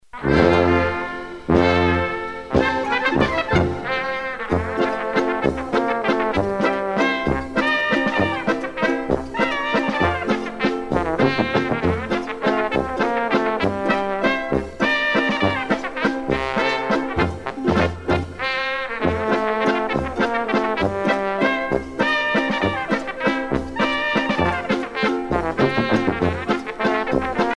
danse : valse
Pièce musicale éditée